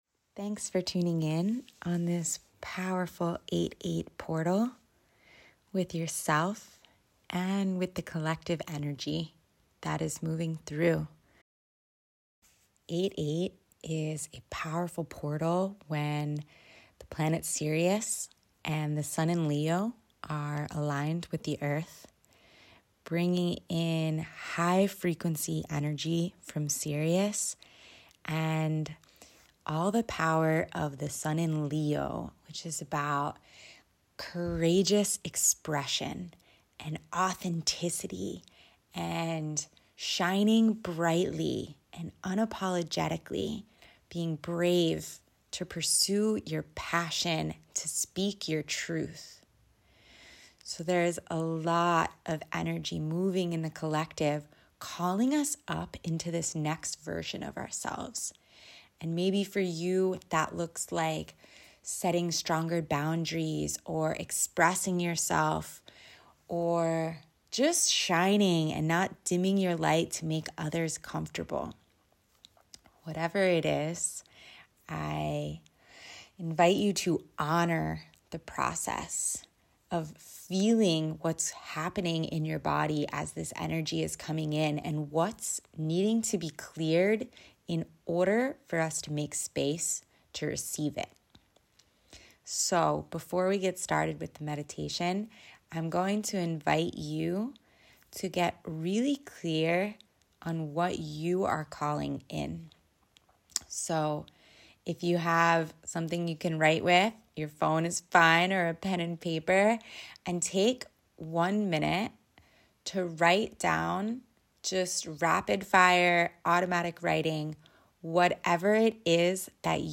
88Meditation.mp3